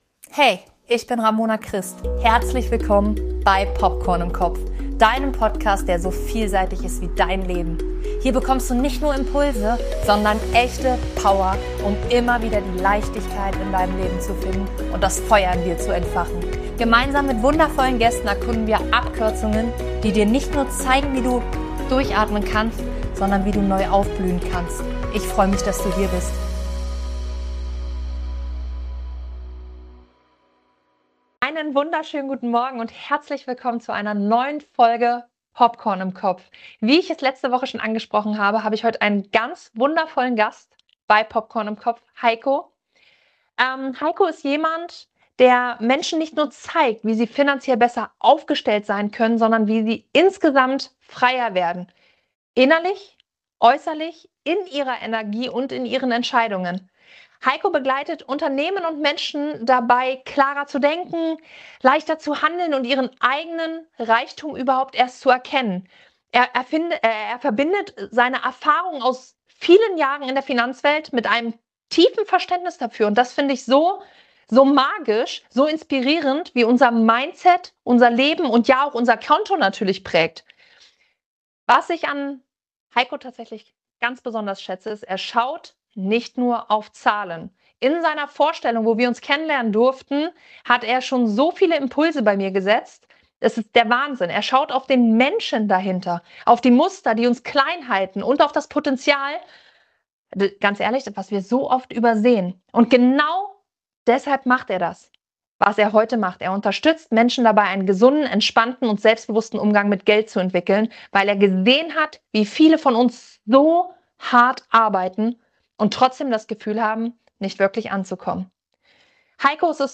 Es ist ein Gespräch, das dich packt.